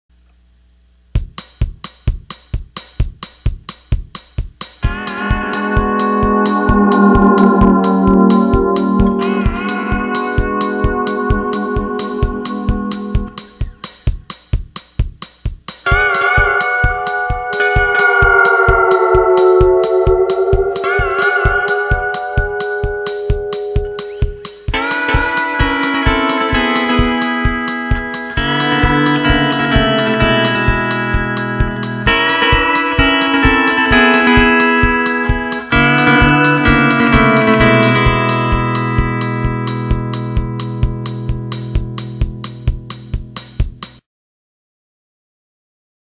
The echo delay is set for 346 millisecond delay.
Fast Echo Tab 4.wma